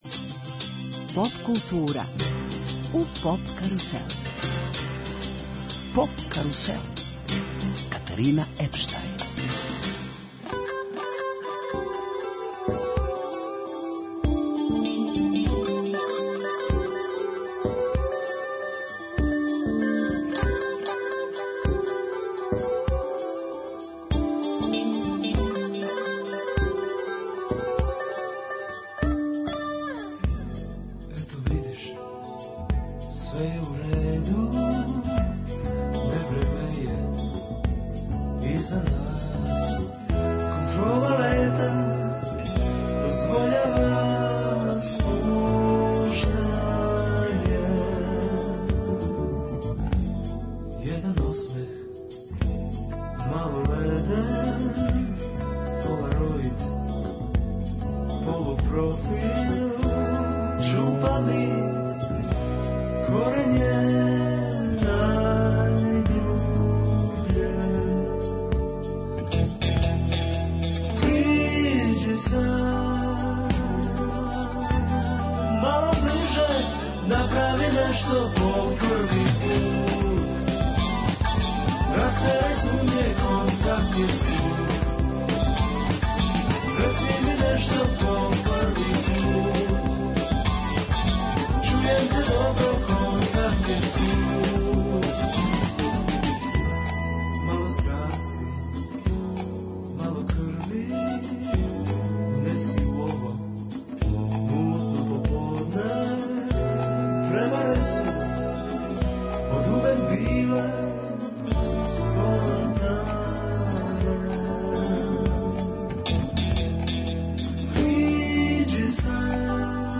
Гост емисије је музички уметник Влатко Стефановски, поводом последњег музичког издања и наступајућег концерта, у Београду.